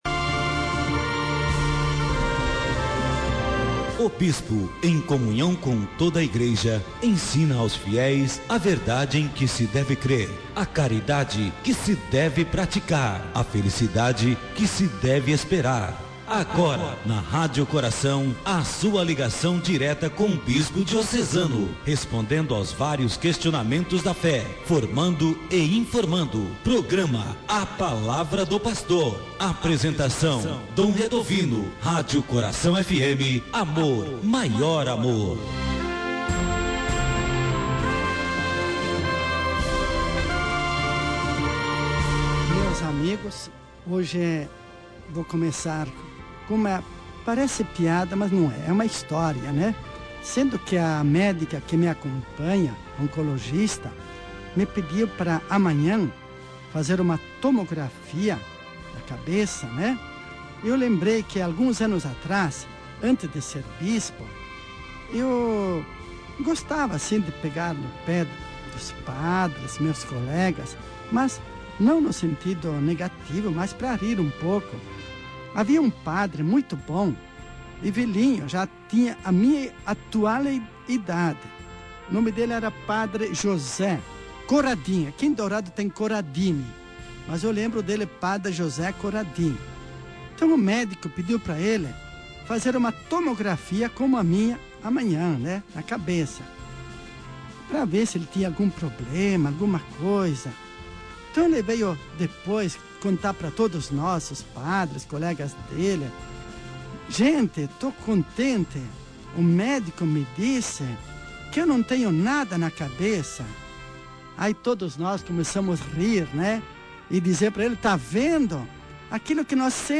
Dom Redovino fala sobre resgate da vida de ex-modelo